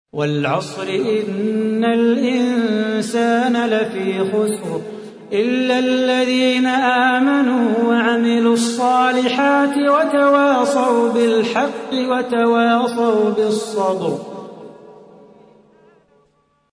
تحميل : 103. سورة العصر / القارئ صلاح بو خاطر / القرآن الكريم / موقع يا حسين